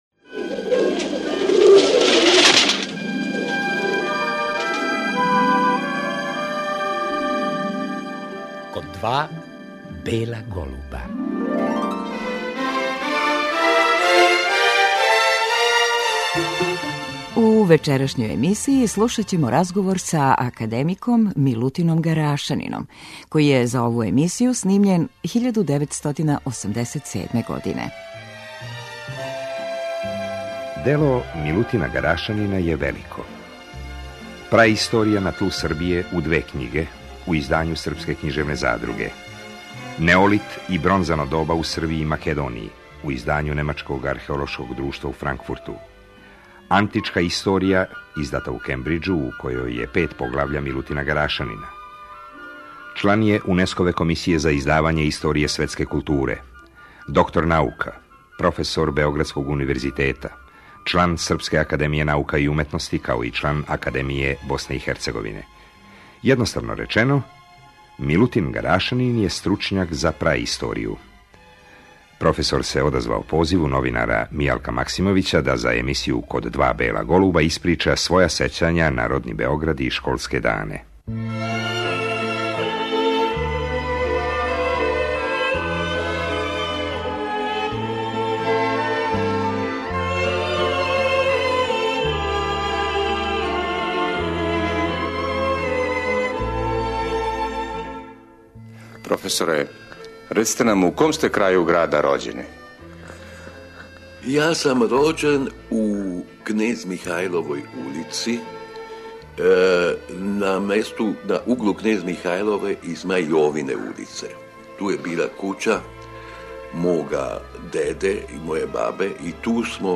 О старом Београду научник је за ову емисију говорио 1987. године.